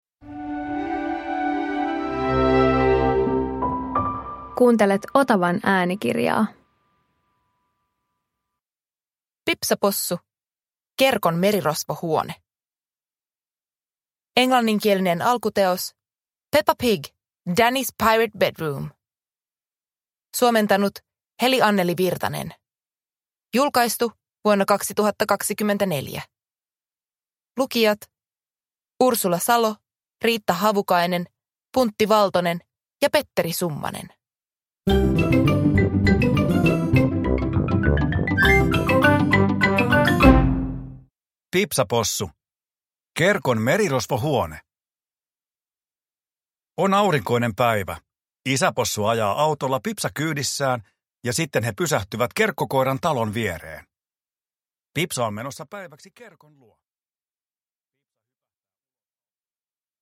Pipsa Possu - Kerkon merirosvohuone – Ljudbok